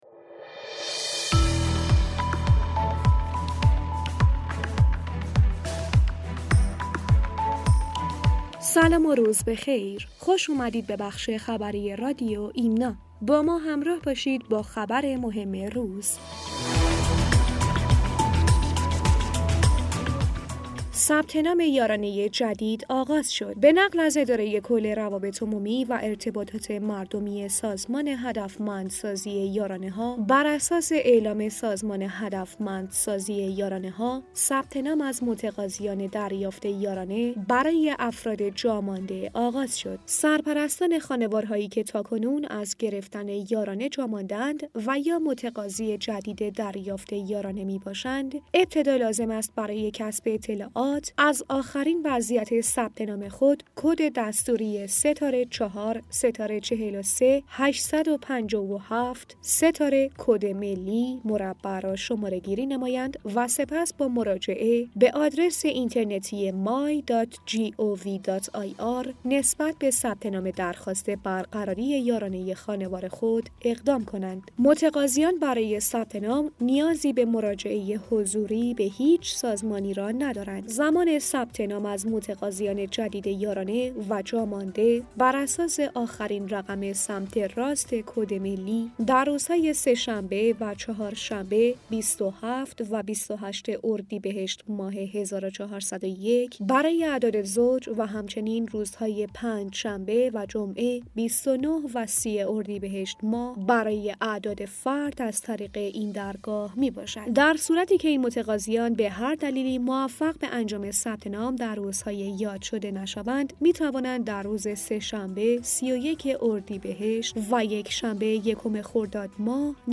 رادیو خبری ایمنا/